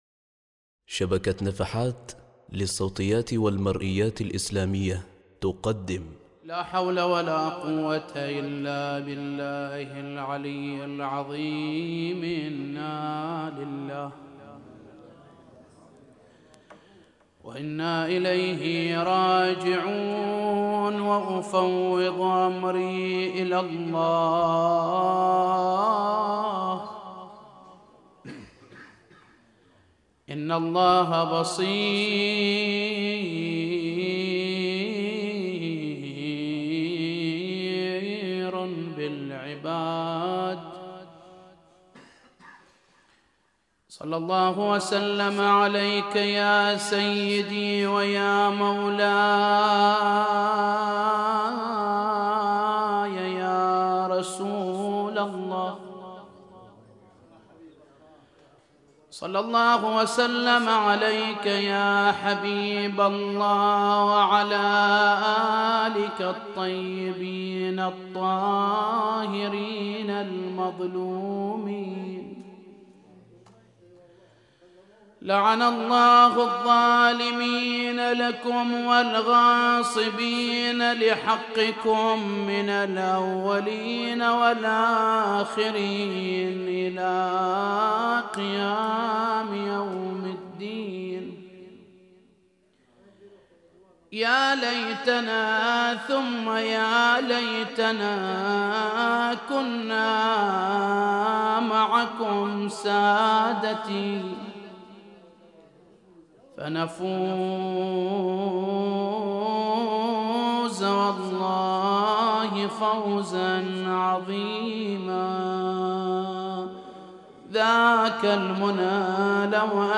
مجلس مصاب السيدة فاطمة الزهراء عليها السلام |